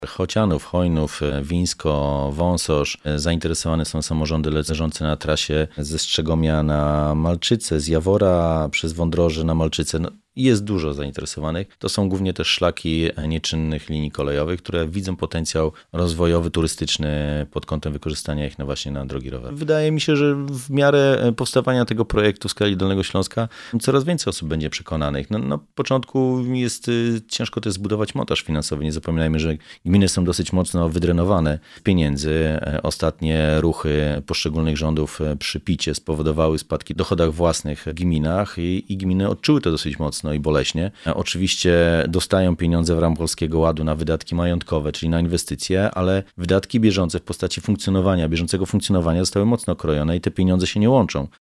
Tymoteusz Myrda – Członek Zarządu Województwa Dolnośląskiego wymienia samorządy, które zdecydowały się na Cyklostradę.